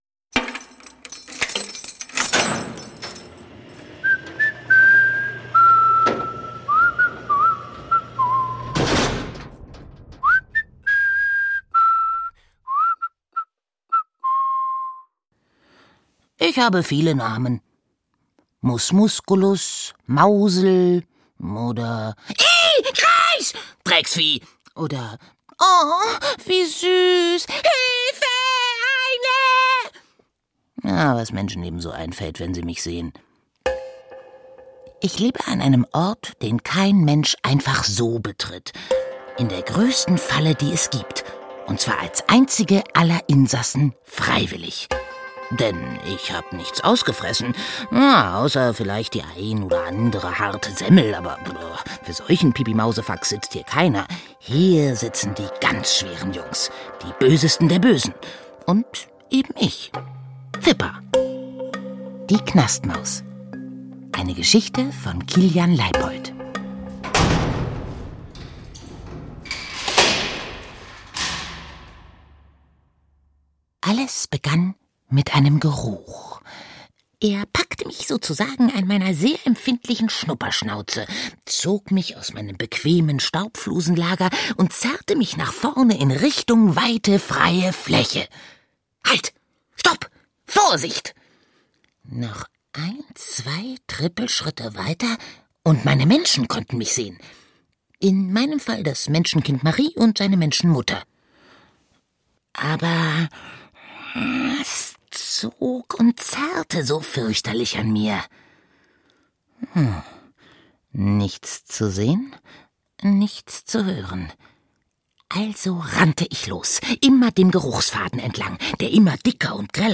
Lesung für Kinder: Die Knastmaus.
Abenteuer im Kopf: Hier findet ihr lustige, spannende und informative Kinder-Hörspiele und Lesungen.